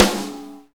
normal-hitclap.mp3